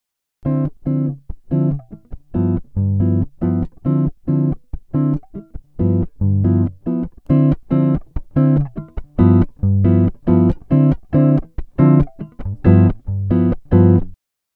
Жму на запись - сигнал... как-будто гейна слегка наваливается, ну и пишется соответственно так же.
Прилагаю запись в линию, сухой DI. Первые 4 такта в байпасе, следующие 4 - в режиме записи.